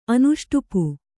♪ anuṣṭupu